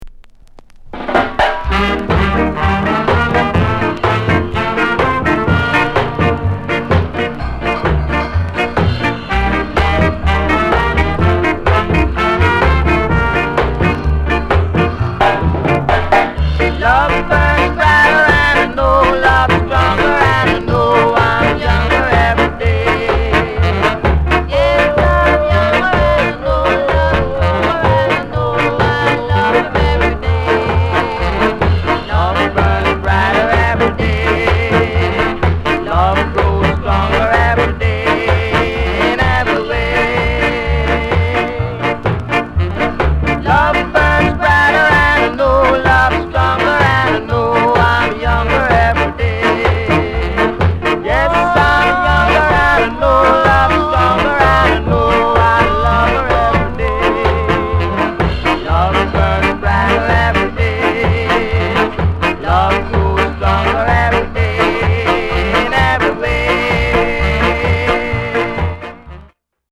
KILLER SKA INST